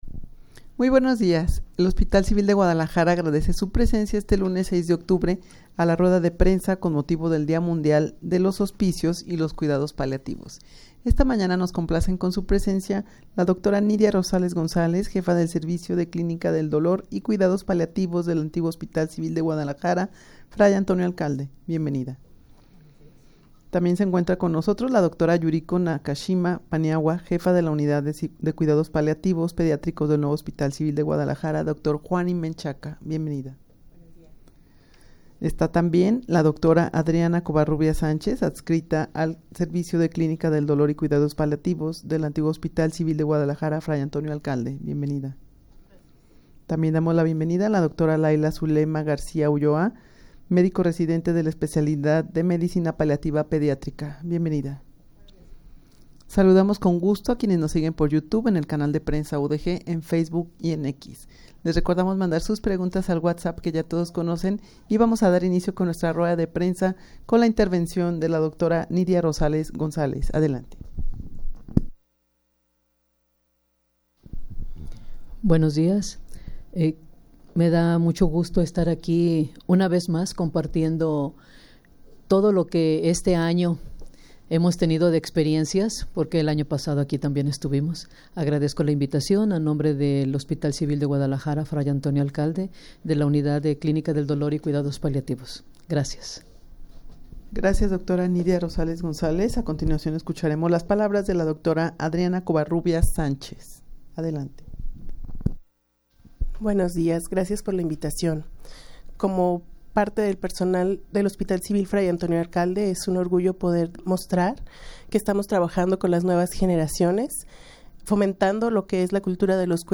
rueda-de-prensa-con-motivo-del-dia-mundial-de-los-hospicios-y-los-cuidados-paliativos.mp3